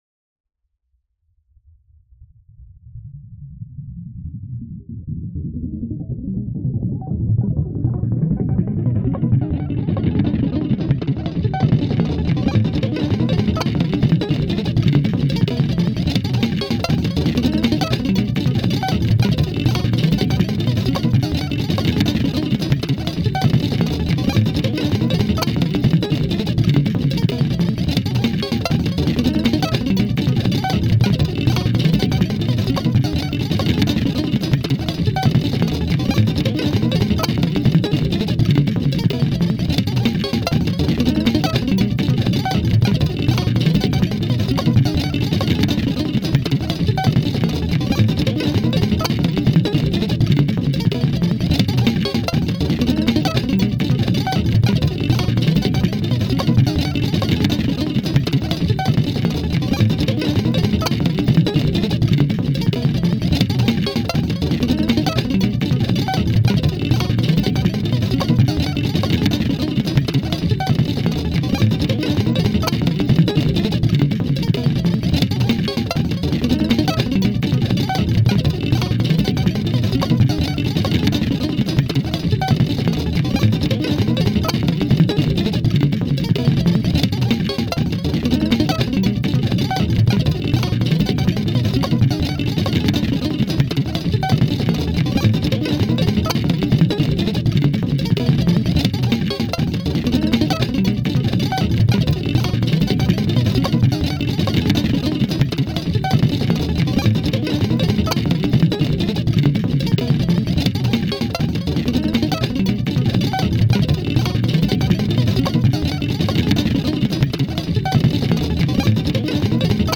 呪術的、儀式的な、プレクティクス・パルス・ミュージック。
パルスによる超感覚的な酩酊感。
パルスにおけるプレクティクス・ギター・オーケストレーション。